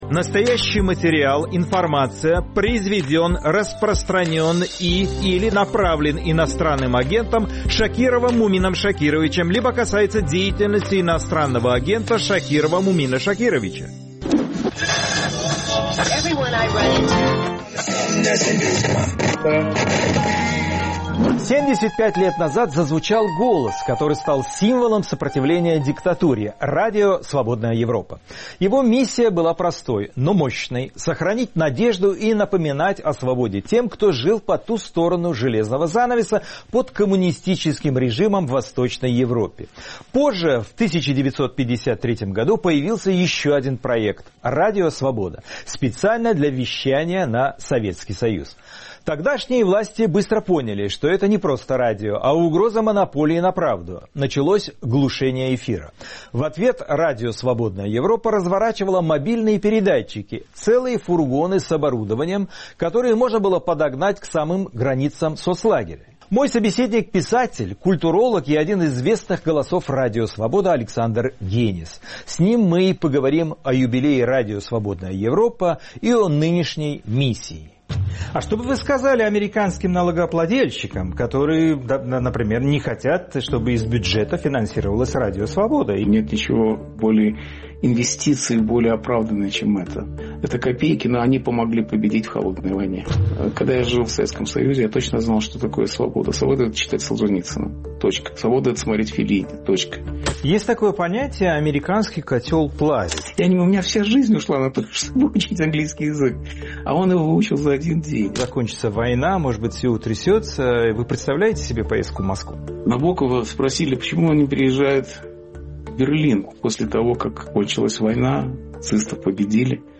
Александр Генис — писатель, культуролог — в разговоре о войне, эмиграции и разочарованиях.